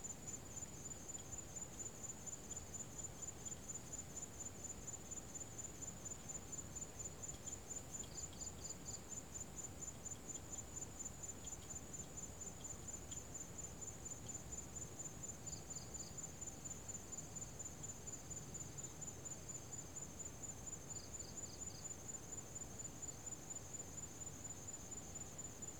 field_night.ogg